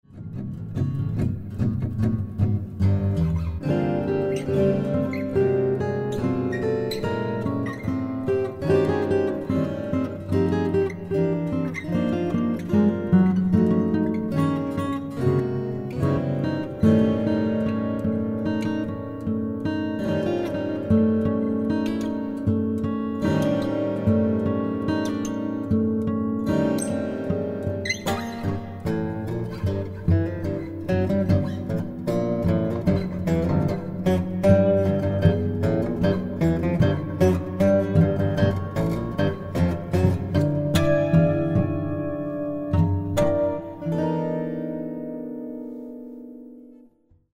baritone guitar duets